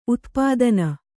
♪ utpādana